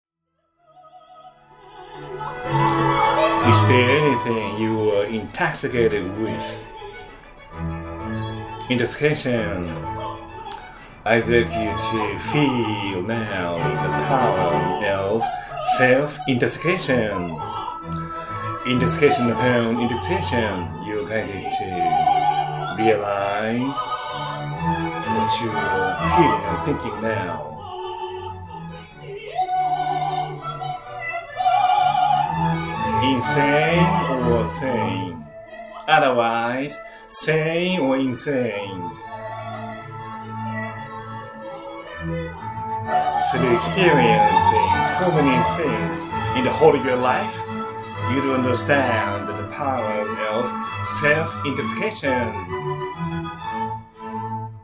■Recitative-style lecture （”lecture dramatized” in the opera） ⇒ The power of self-intoxication